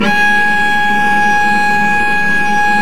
Index of /90_sSampleCDs/Roland L-CD702/VOL-1/STR_Vc Marc&Harm/STR_Vc Harmonics